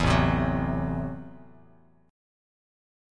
Listen to Am6 strummed